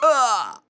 damage_flying.wav